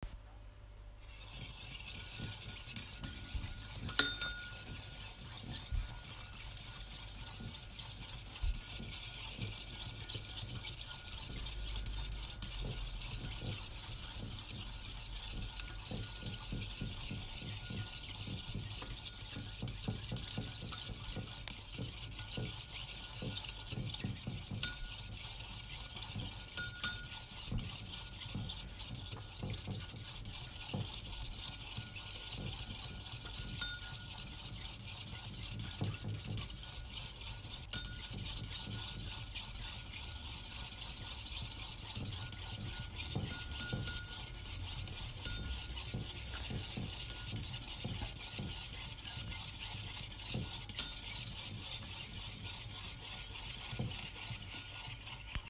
Whisk
Kitchen_Whisk-sounds-2.m4a